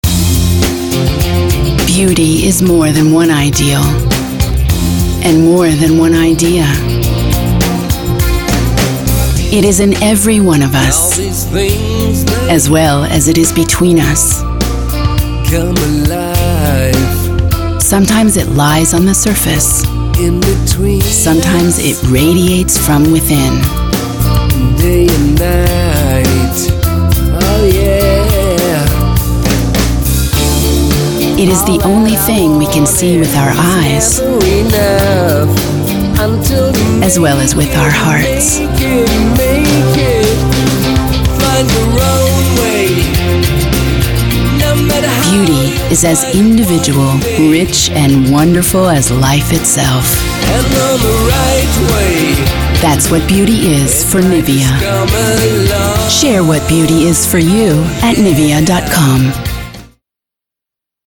American Voice-Over Artist Native Sprecherin US Englisch
Sprechprobe: Werbung (Muttersprache):